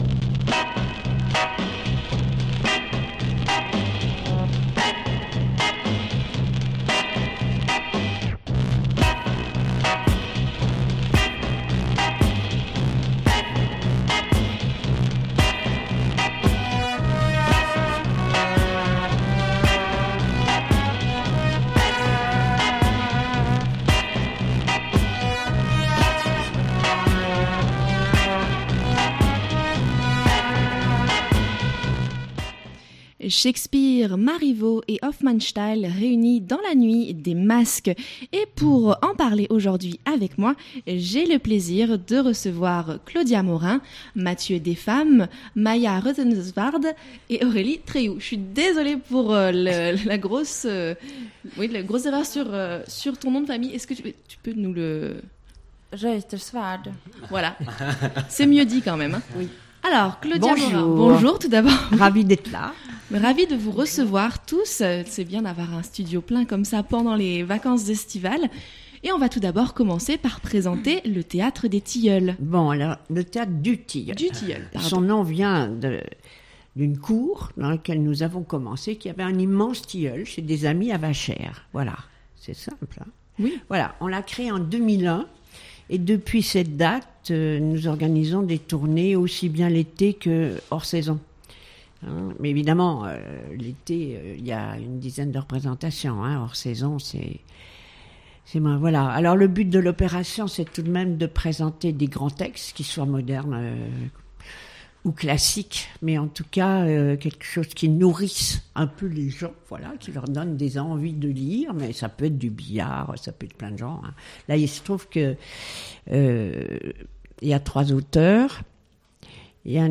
Une plongée dans le monde cruel des acteurs où les genres se mélangent et les intrigues se tissent à découvrir pendant tout le mois de juillet. Ecoutons-les nous en livrer des extraits.